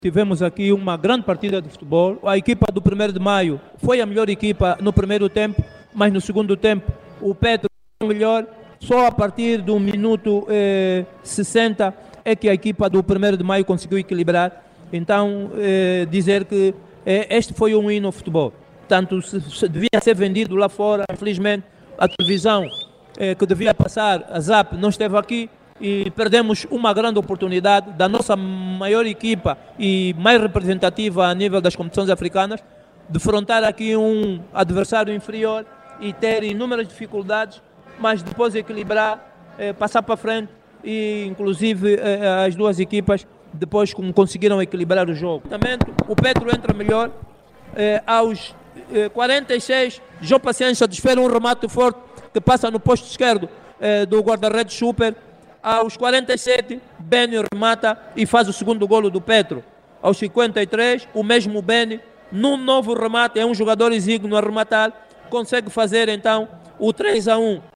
O duelo entre históricos termina com vitória do Petro de Luanda ante o 1º de Maio de Benguela por 3 – 2  para a 13ª jornada  do nacional de futebol. A Análise do duelo entre proletários e tricolores com o comentador